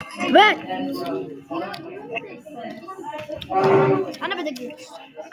voice record soundboard # meme